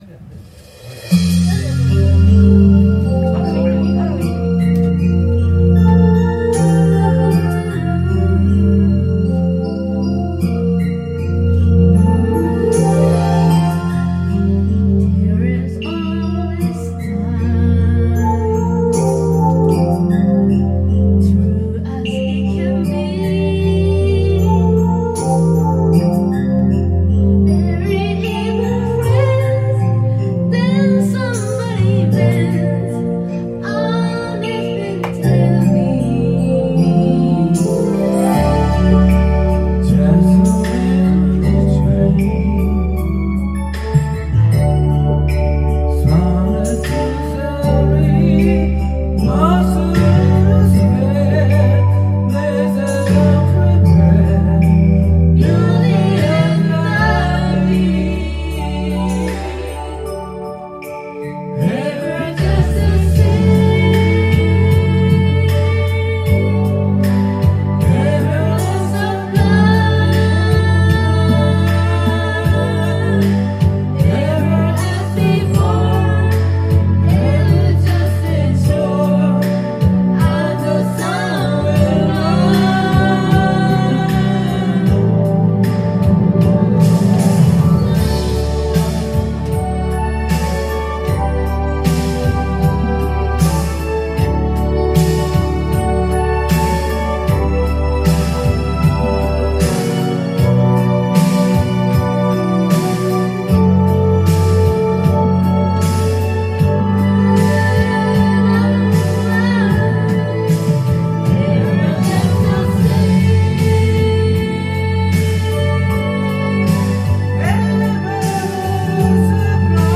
Duet & Chorus Night Vol. 21 TURN TABLE